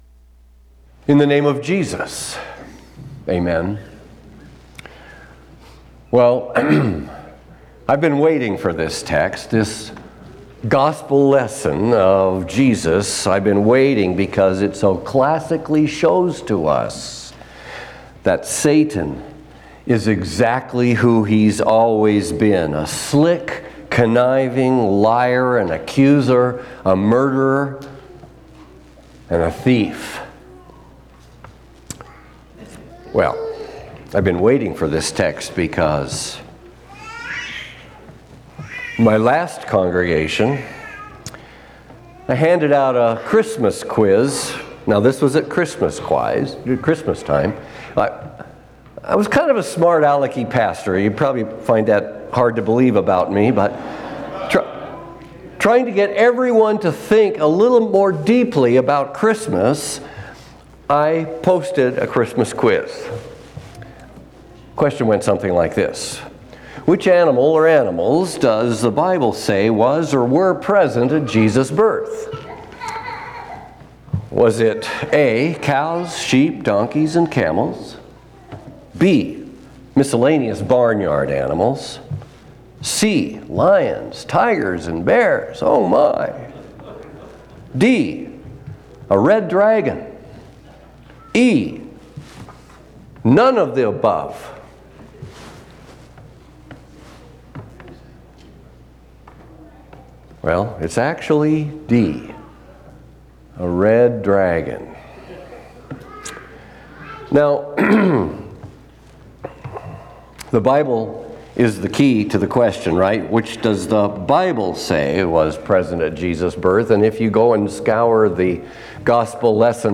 First Sunday in Lent&nbsp